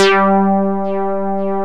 MOOGBASS1 G4.wav